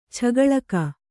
♪ chagaḷaka